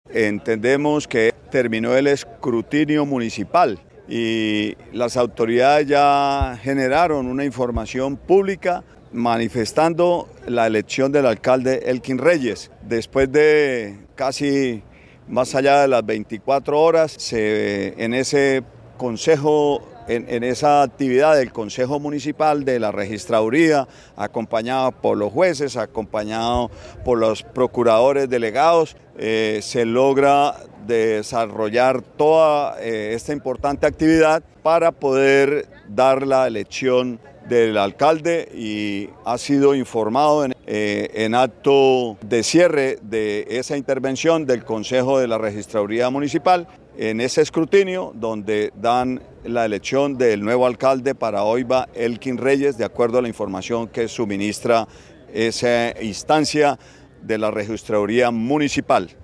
Oscar Hernández, Secretario del Interior de Santander